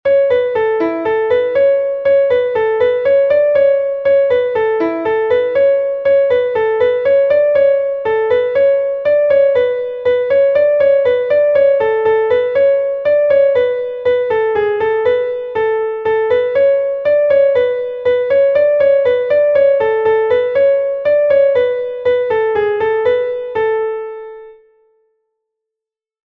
Laridé from Brittany